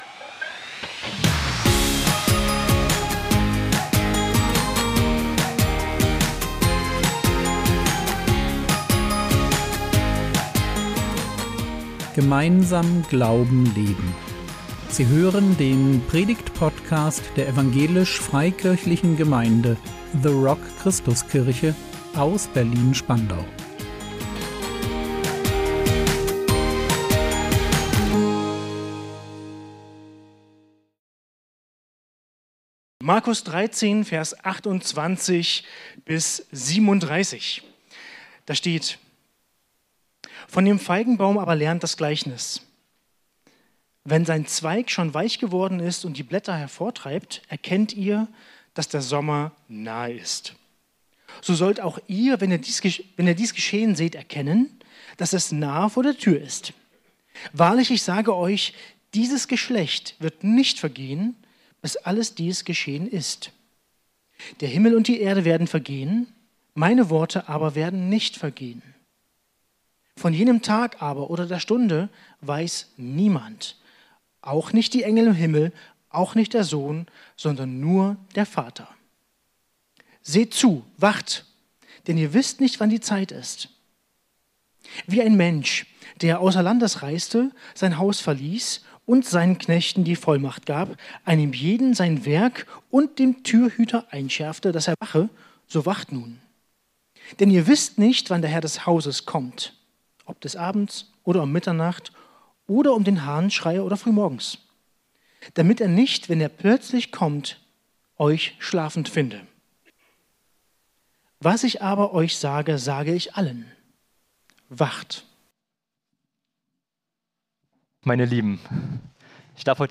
Sehen. Vertrauen. Handeln. | 12.04.2026 ~ Predigt Podcast der EFG The Rock Christuskirche Berlin Podcast
Impuls